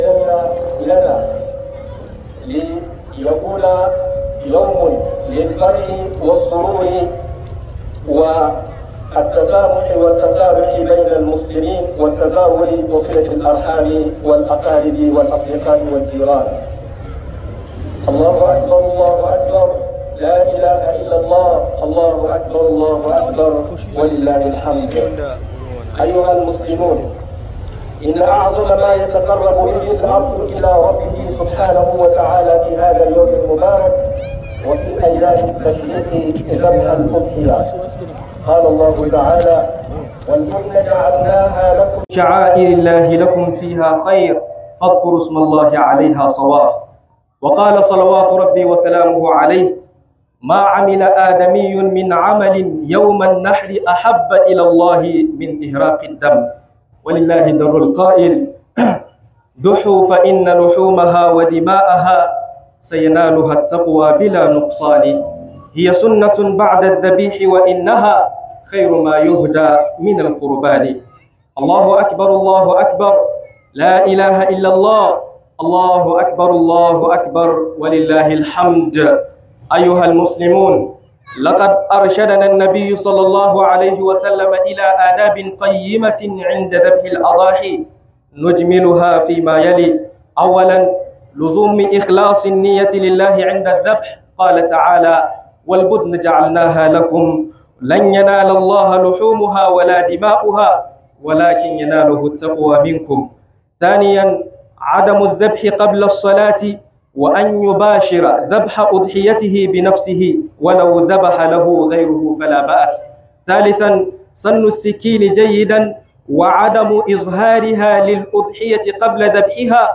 Hudubar sallah babba 2025